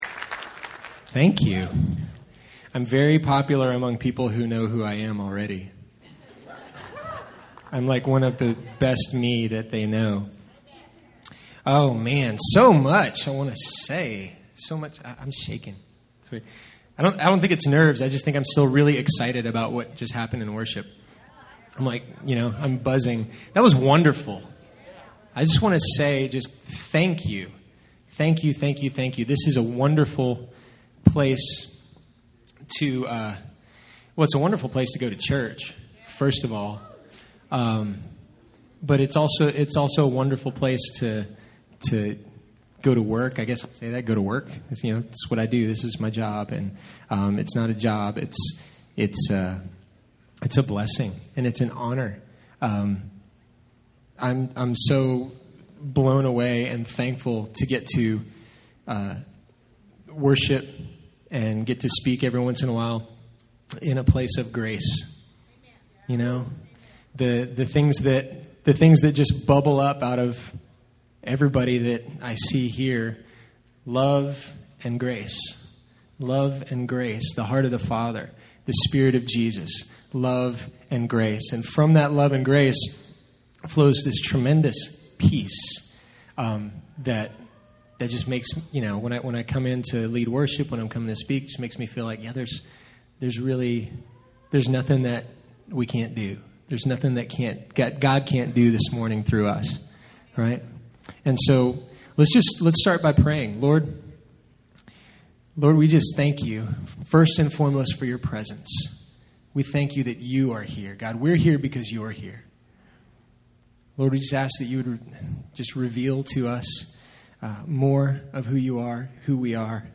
Sermons Archive - Page 18 of 25 - Austin Cathedral